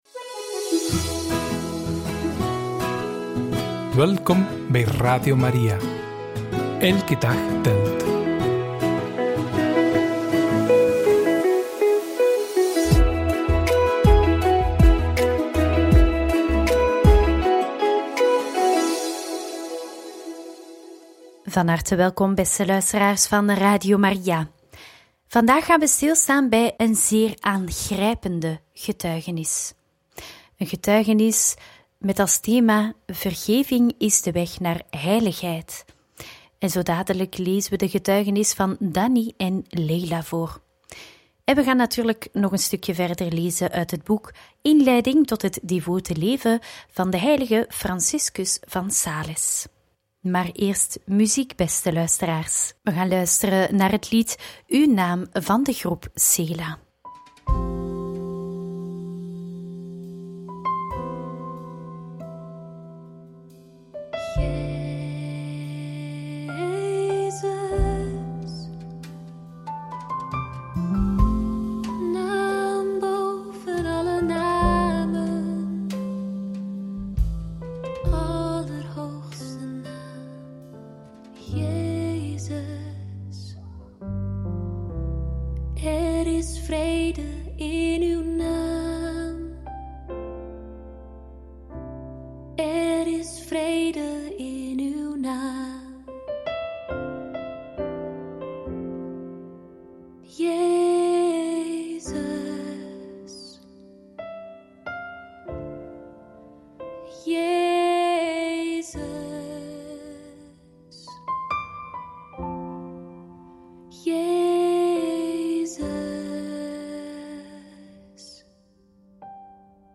Aangrijpende getuigenis